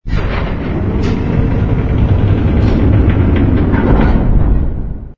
ring_close.wav